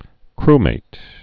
(krmāt)